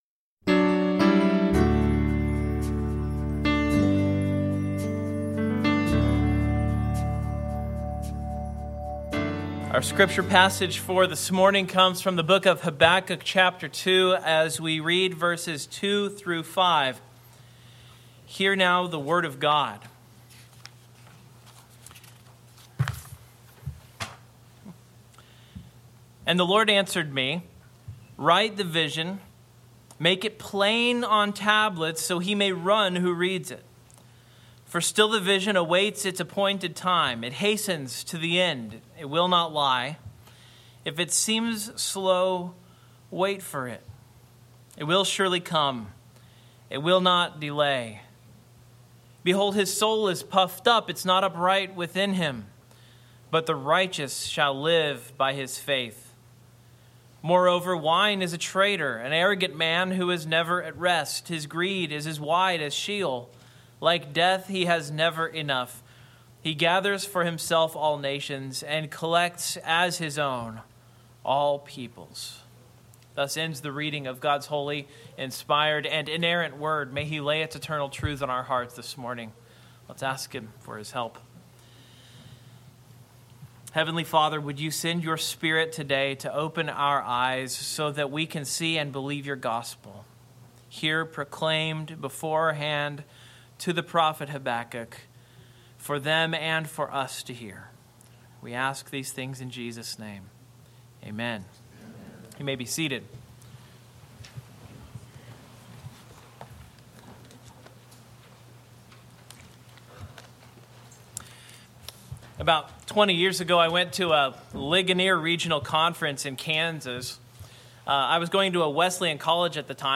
Habakkuk 2:2-5 Service Type: Morning Outline